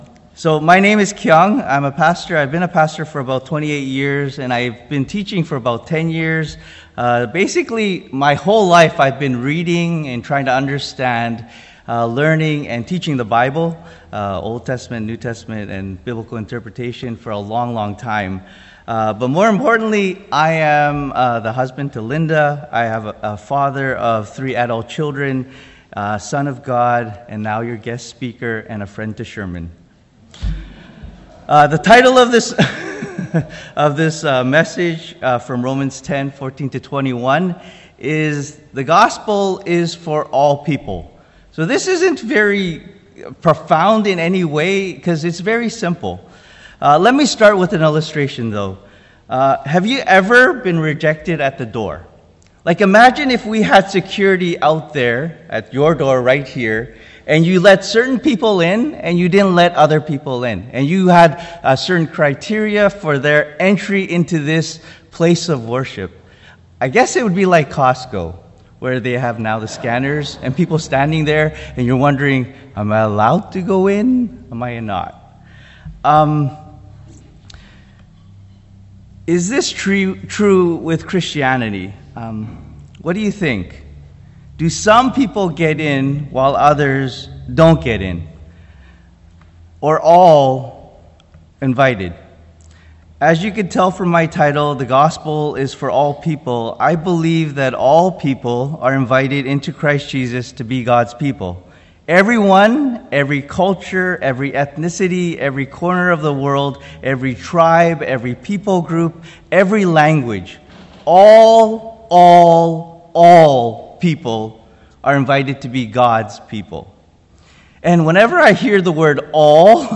The video in the sermon is from the Bible Project, see The Gospel of the Kingdom.